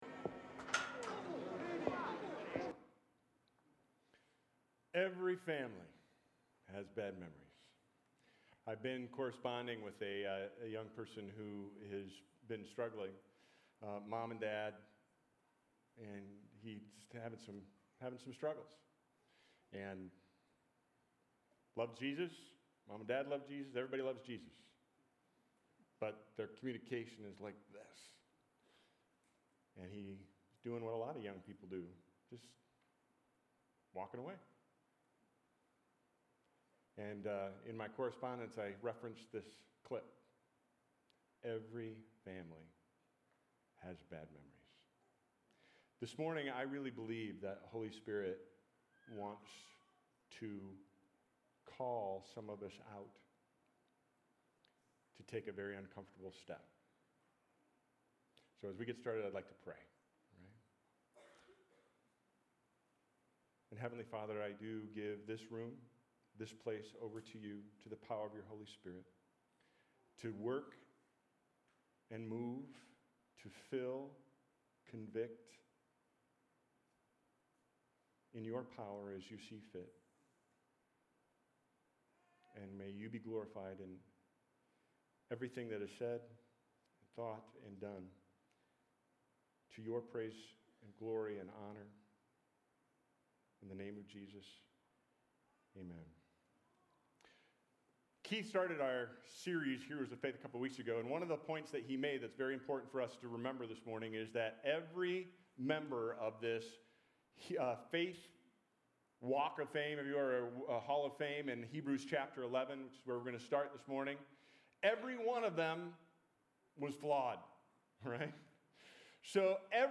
Last Sunday I gave the message in the auditorium of Third Church. It was part of a series on “Heroes of the Faith” from Hebrews 11, and I was asked to unpack the story of Isaac and his twin sons, Esau and Jacob.
Because of copyright laws, the audio of two video clips were deleted from the recording: